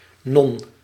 Ääntäminen
Ääntäminen Tuntematon aksentti: IPA: /noːn/ Haettu sana löytyi näillä lähdekielillä: hollanti Käännöksiä ei löytynyt valitulle kohdekielelle.